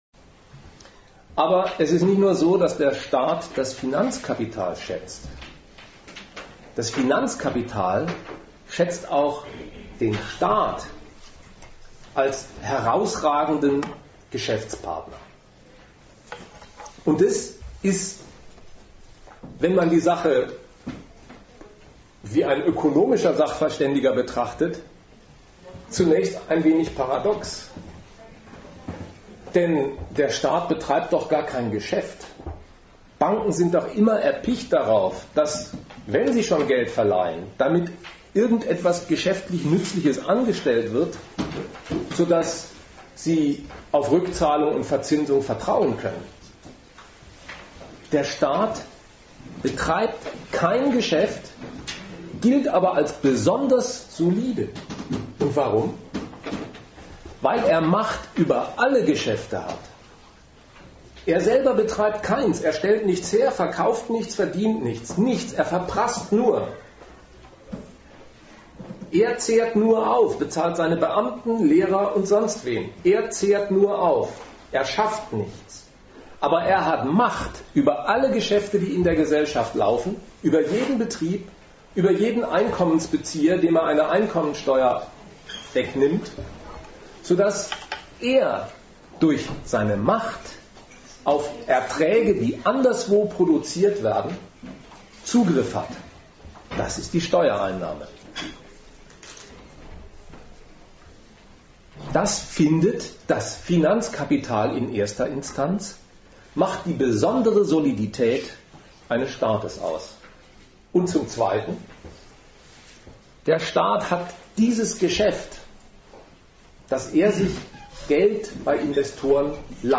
Ort Regensburg
Dozent Gastreferenten der Zeitschrift GegenStandpunkt